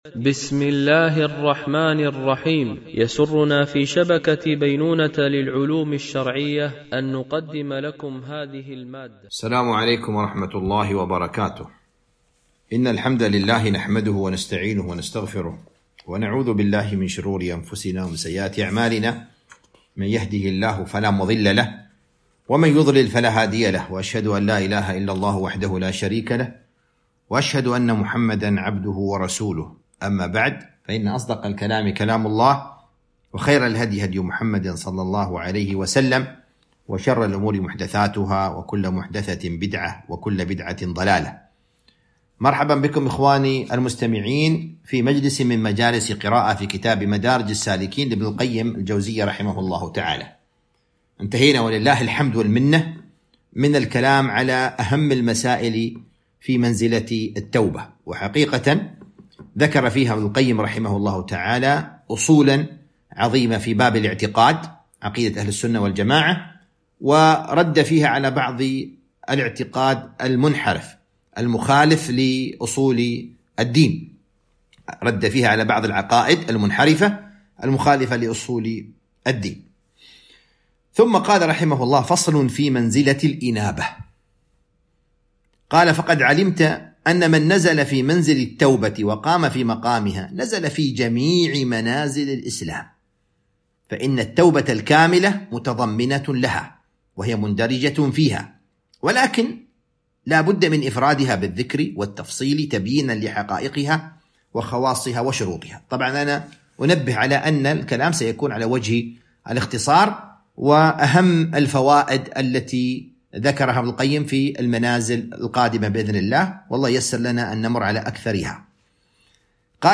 قراءة من كتاب مدارج السالكين - الدرس 46